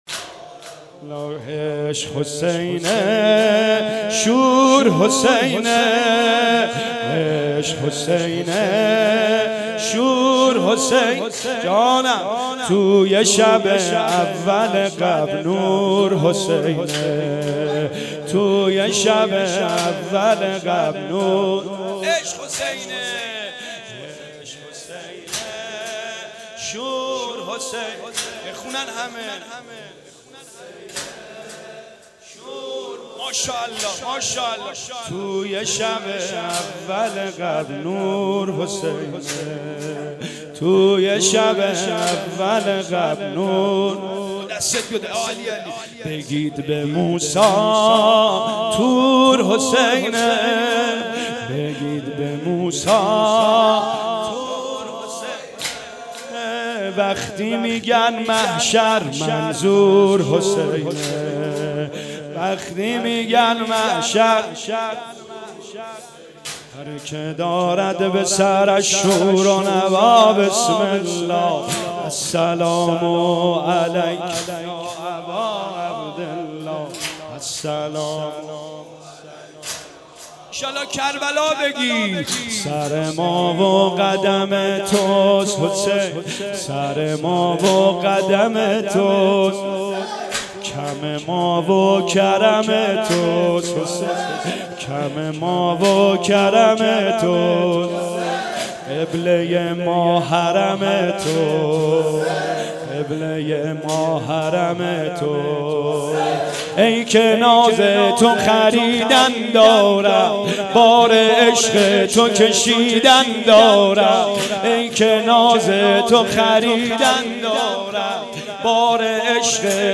شور حسینه-تک